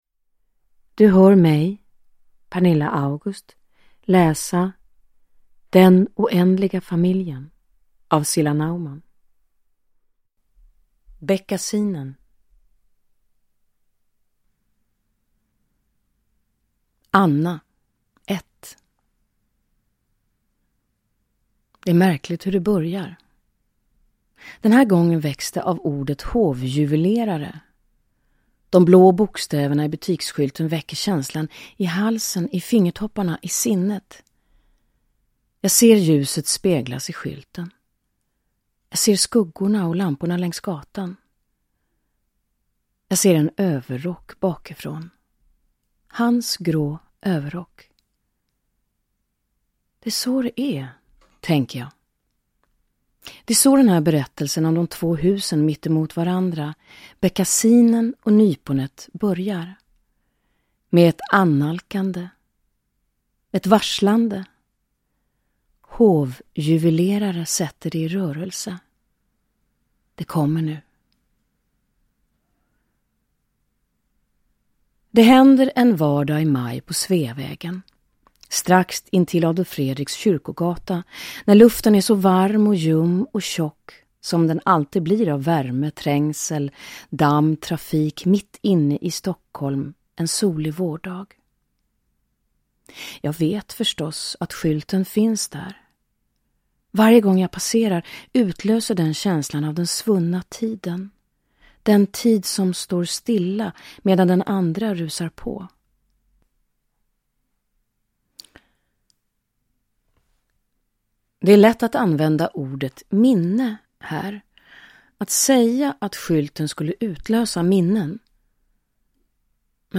Uppläsare: Pernilla August
Ljudbok